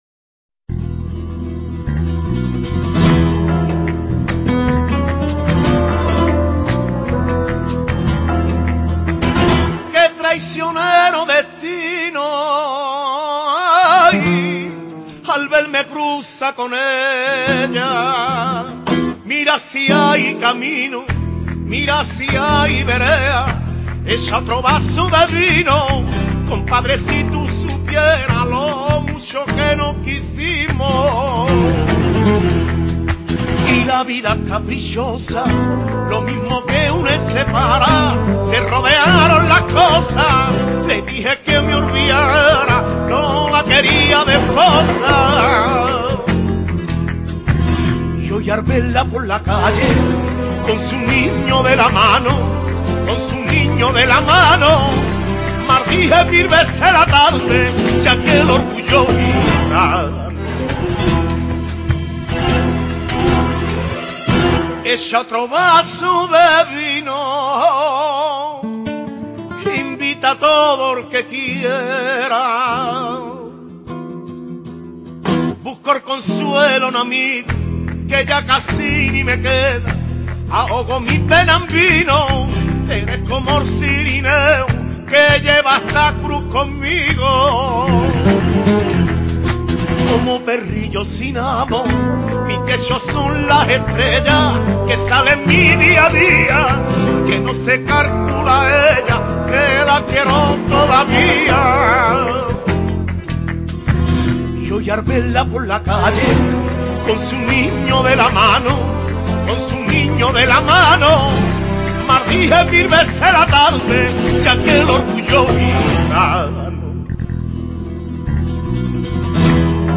una entrega semanal de sevillanas para la historia.
Guitarra
desgarradora voz flamenca